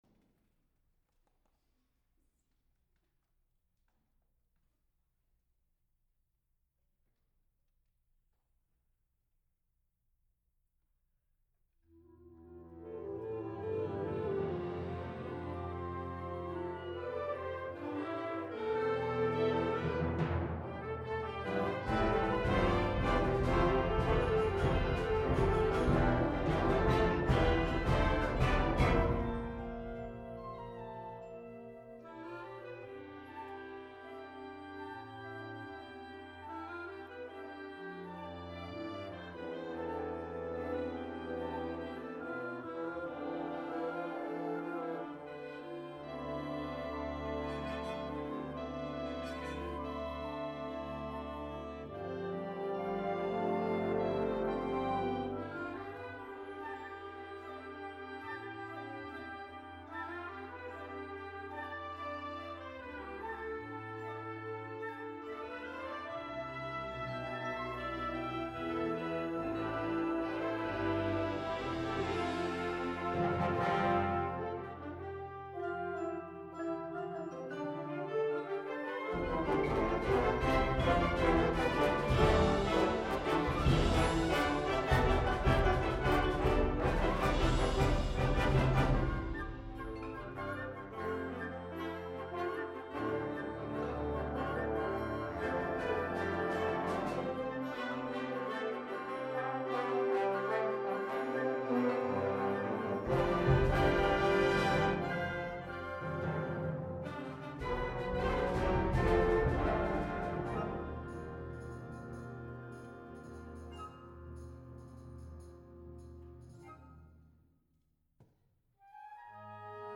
Genre: Band
Marimba
Vibraphone
Percussion 2 (Suspended Cymbal, Tambourine, Triangle)
Chimes, Wind Chimes